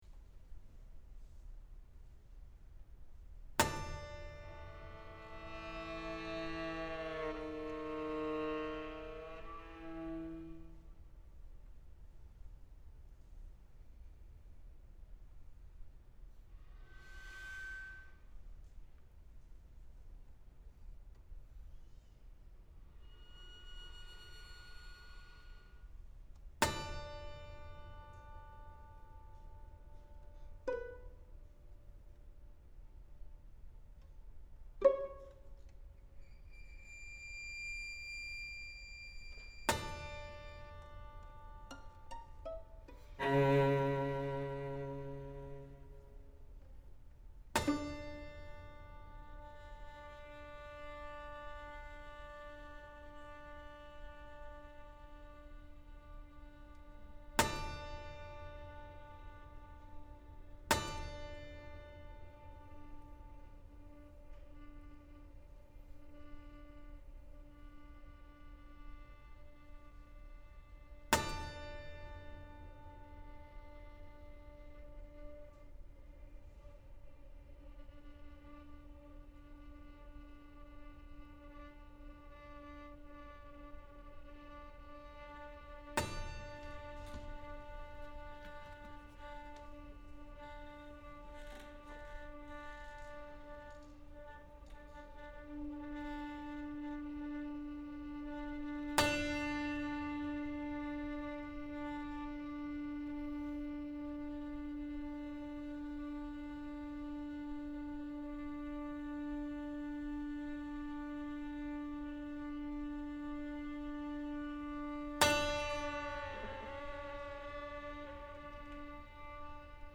Piece for string trio, prepared piano, and clarinet/bass clarinet; performed May, 2012 by the excellent counter)induction.